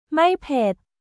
マイ・ペッ